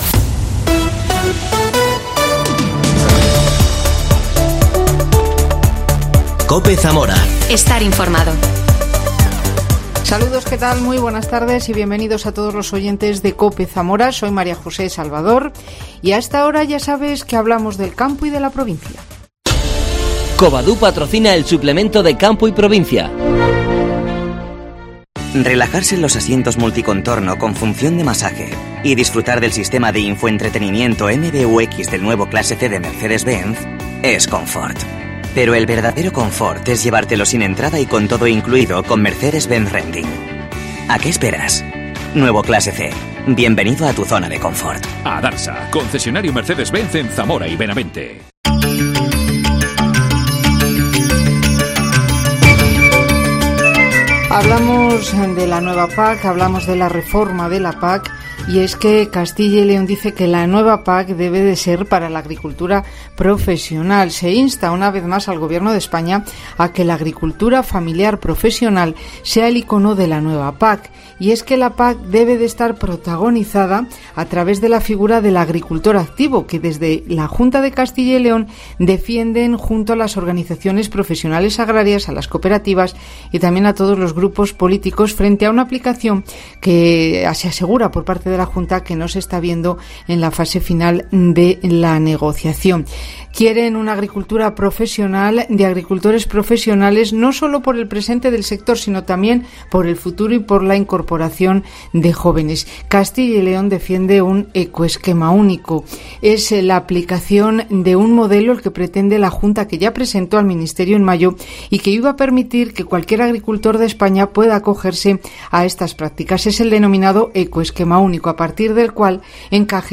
AUDIO: Reportaje sobre la PAC y los ecoesquemasLonja de Salamanca